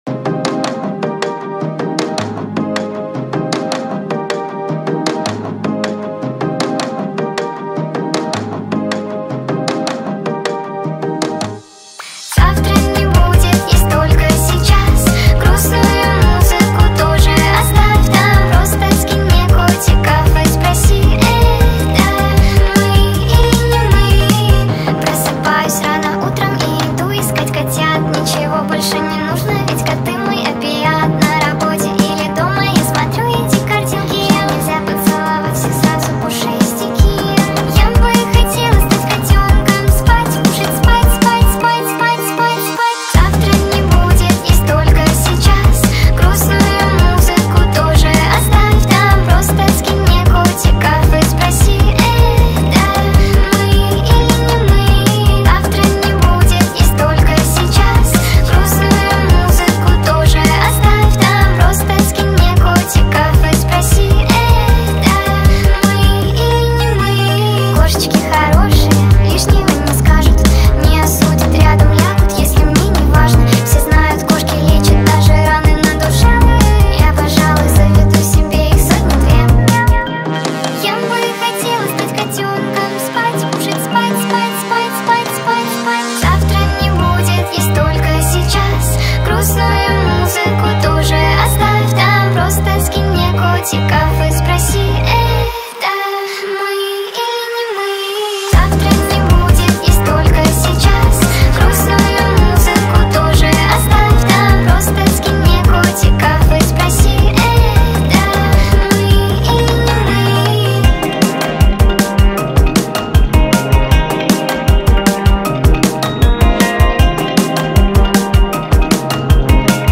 speed up remix
TikTok remix